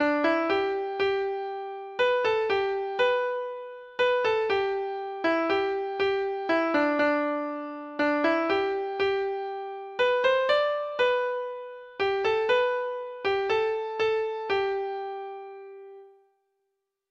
Folk Songs from 'Digital Tradition' Letter C Can that Circle be Unbroken?
Treble Clef Instrument  (View more Intermediate Treble Clef Instrument Music)
Traditional (View more Traditional Treble Clef Instrument Music)